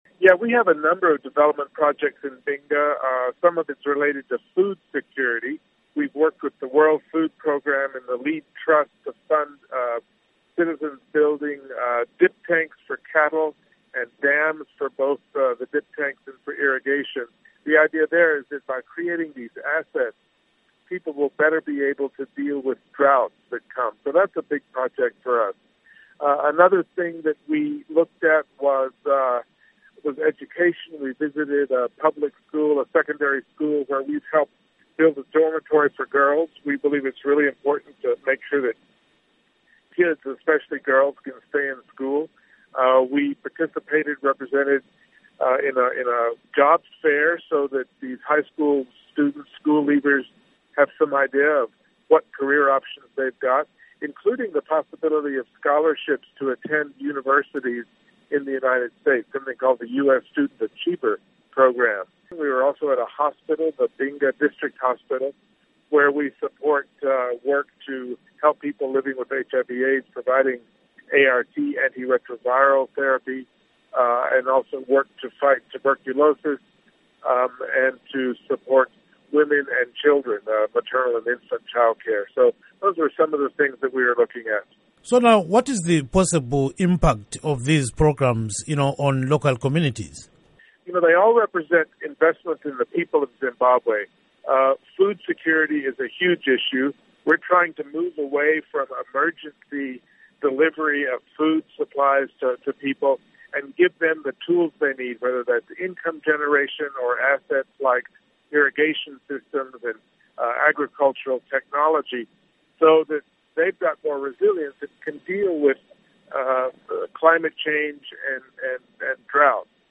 Interview With Ambassador Bruce Wharton on US-Funded Projects